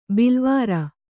♪ bilvāra